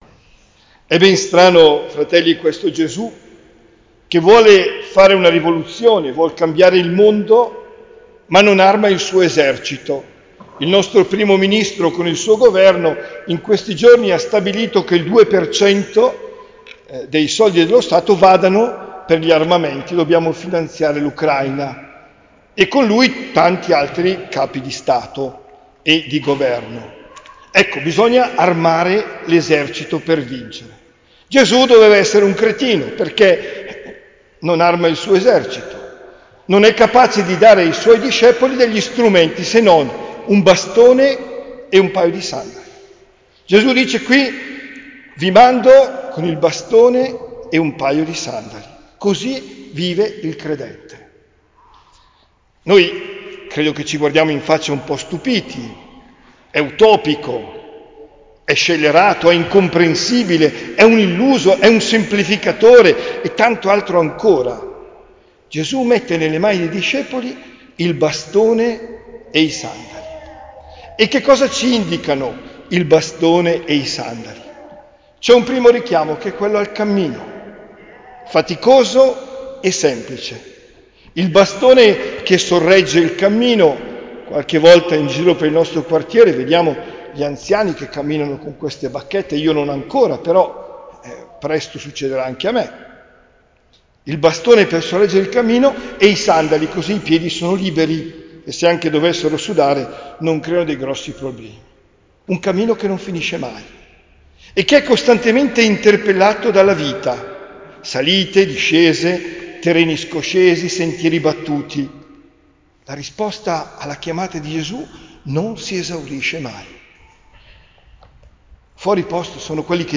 OMELIA DEL 14 LUGLIO 2024